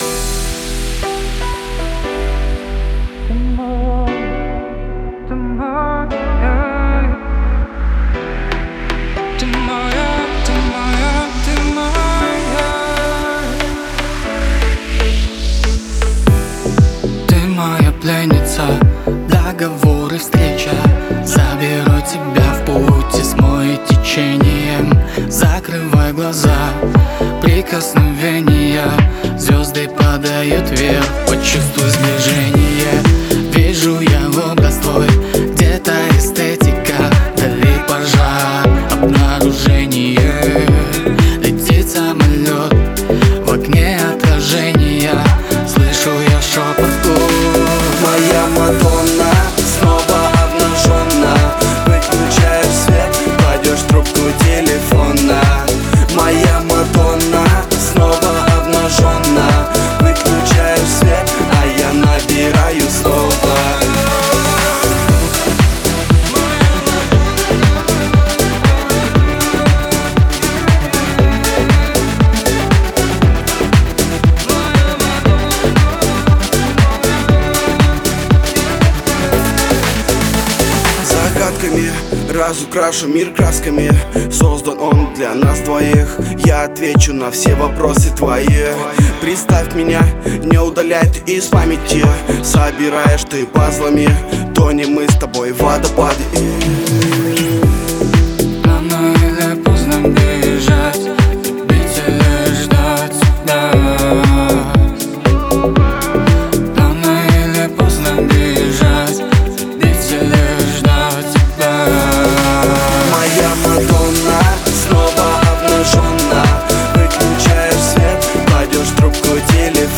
это яркий поп-трек с элементами электроники